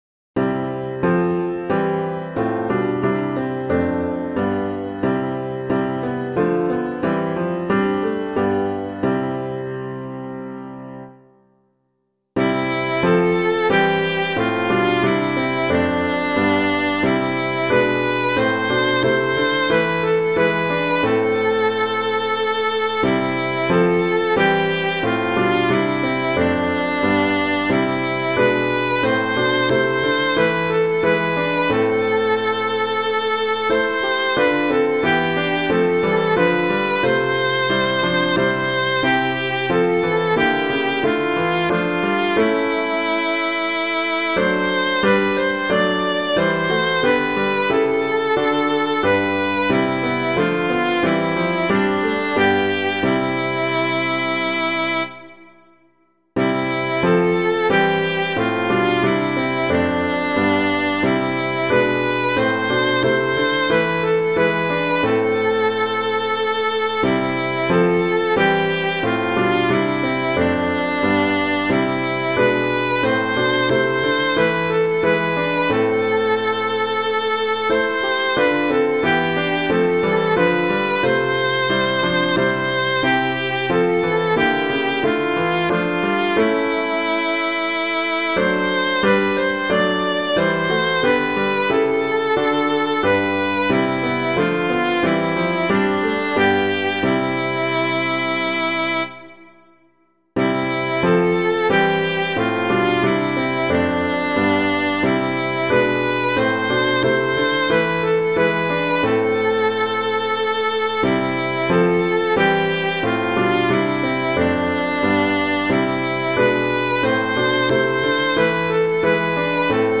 piano
O Sacred Head Sore Wounded [Alexander - PASSION CHORALE] - piano [NLP].mp3